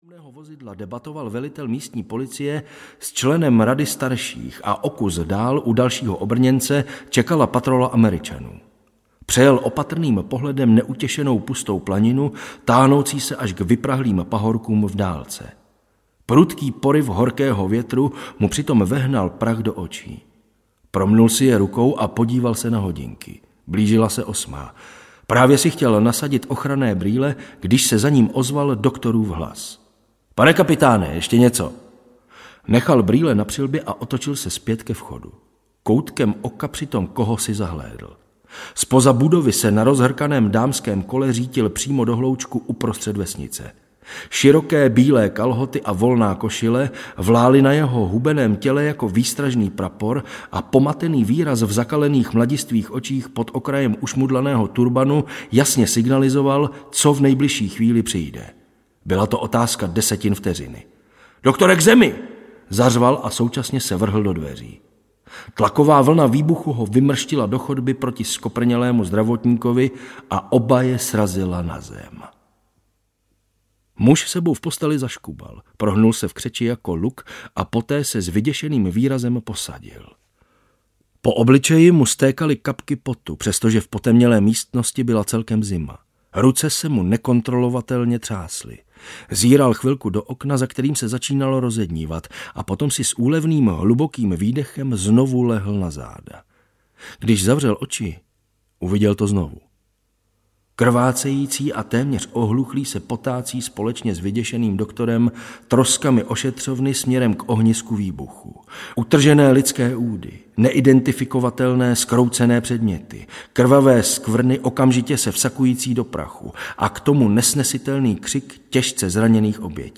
Následuj mne audiokniha
Ukázka z knihy